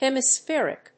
発音記号
• / hèmɪsfí(ə)rɪk(米国英語)
• / ˌhemʌˈsfɪrɪk(英国英語)